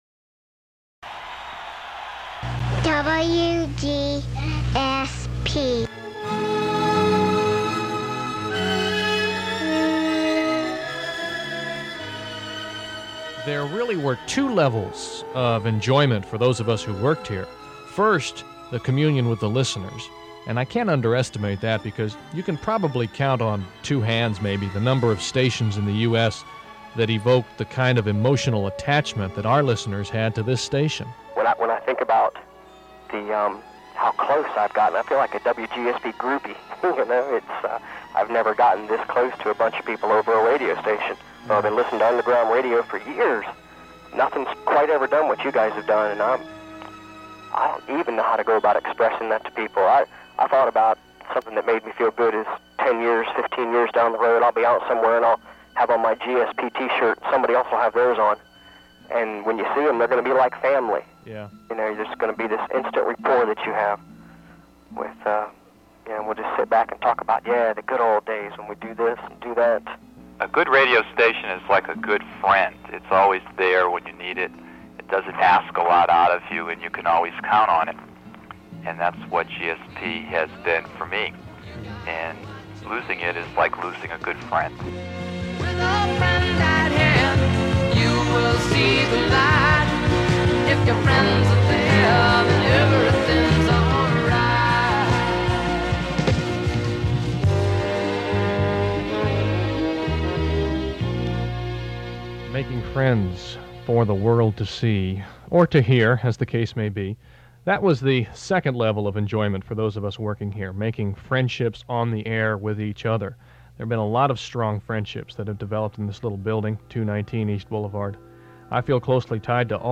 Unlike most stories like this, we DID get the fond farewell broadcast before the switch and on our last day we aired a highly-produced, elaborate, celebratory 2 hours + special that tried to encapsulate what our experiment got right. We included interviews with listeners and the entire remaining staff.